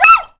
added dog sounds
dsdgpain.ogg